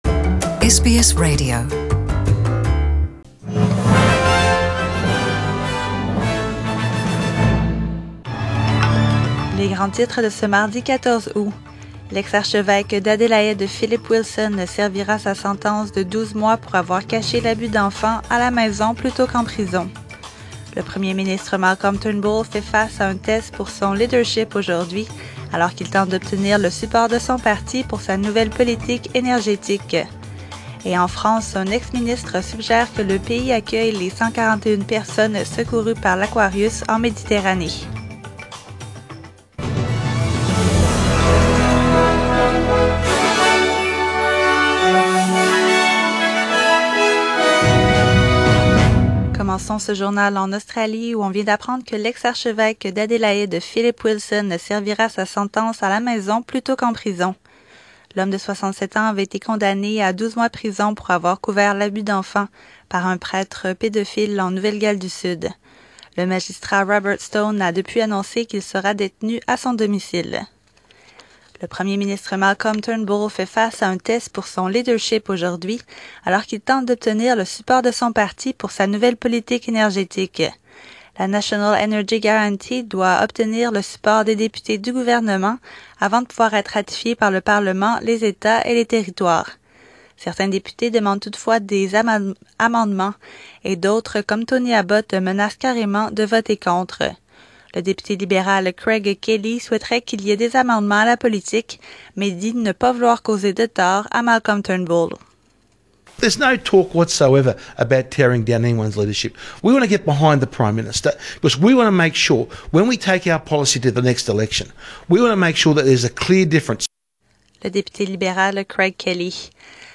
SBS French : Journal du 14 août